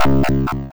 rebound.wav